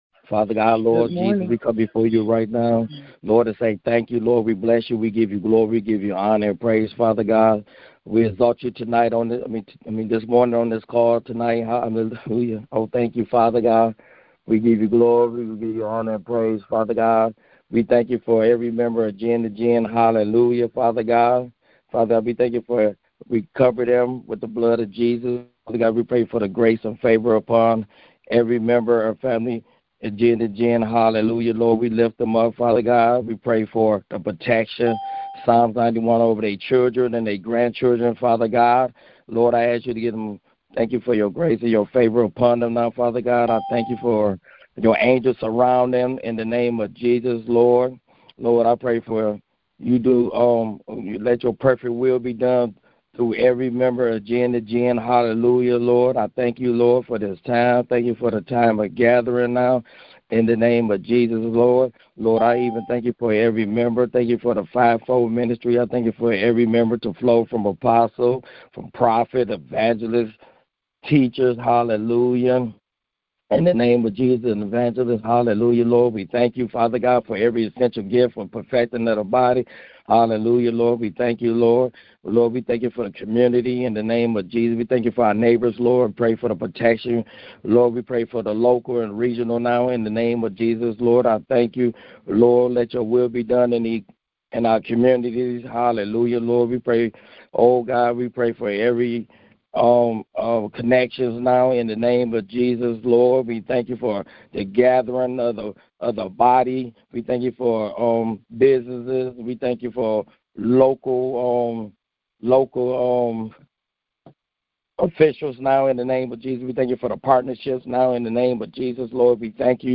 Prayers for children and teens taken from the weekly prayer conference line.